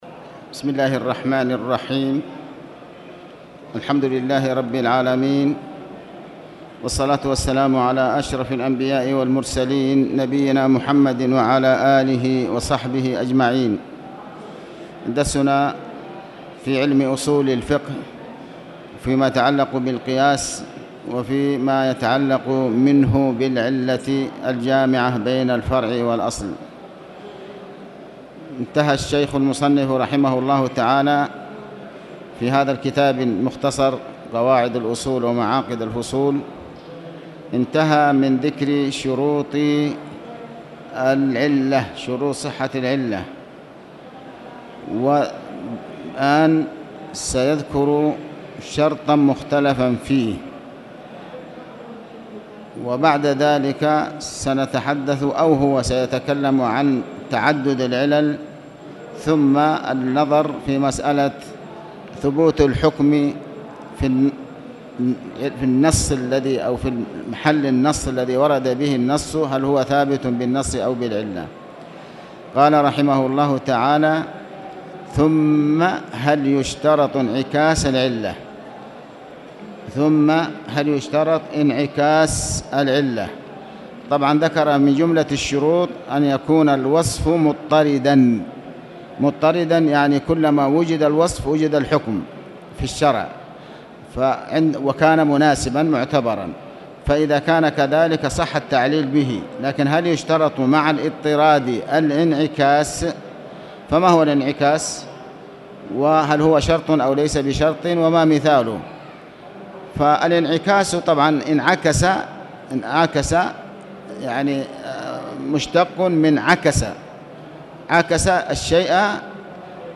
تاريخ النشر ٢٠ ربيع الثاني ١٤٣٨ هـ المكان: المسجد الحرام الشيخ: علي بن عباس الحكمي علي بن عباس الحكمي العلة